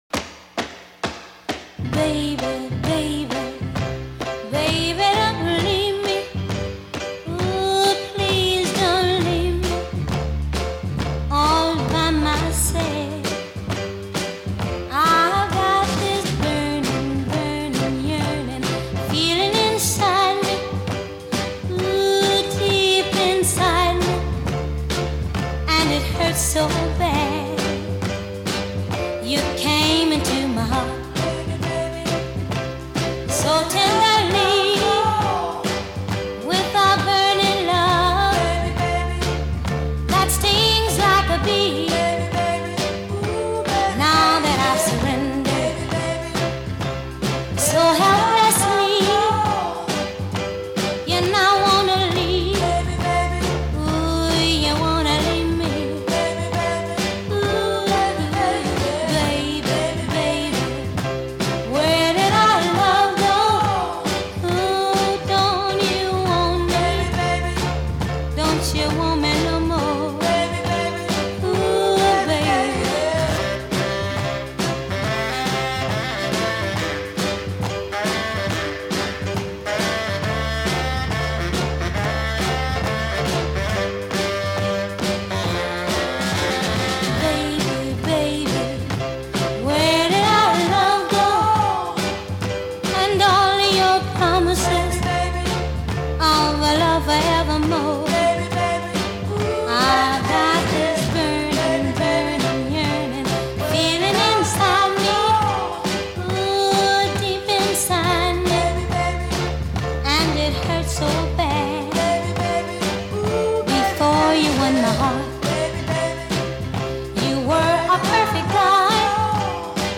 American soul and R&B group